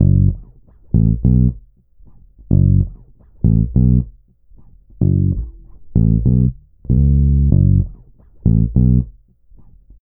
Track 14 - Bass.wav